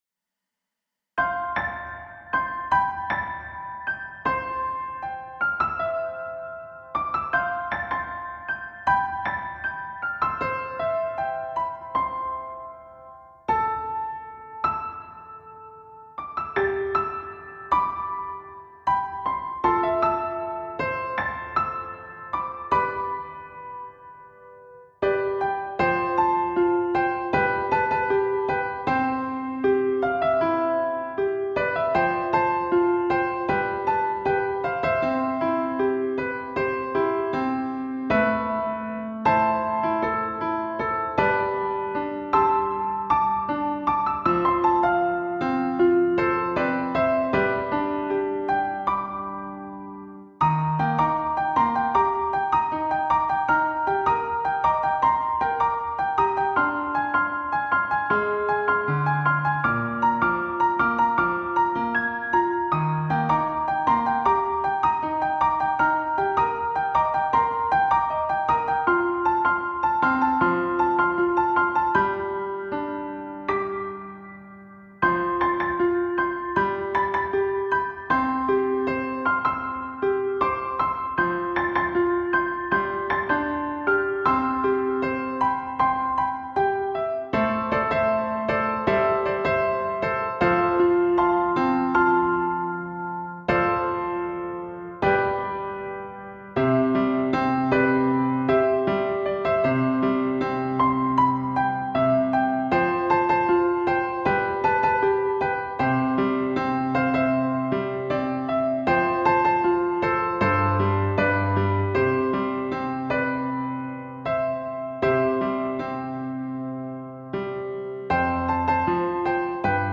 ピアノ伴奏だけのリンバージョンです。
■オケ